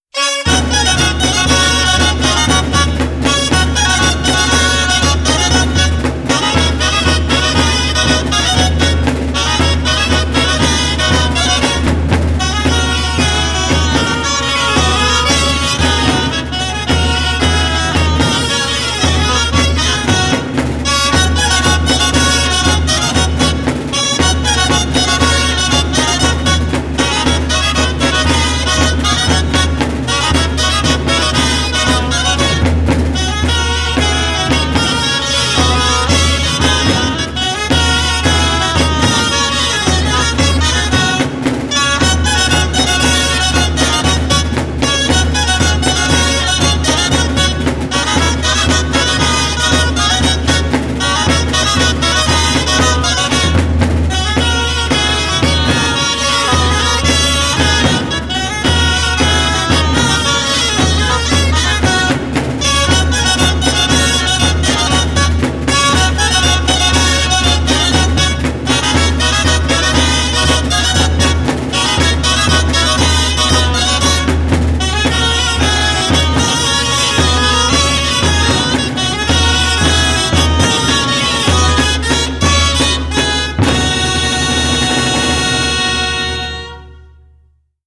19.3 BALL GITANES GALERONS Grallers Tocaferro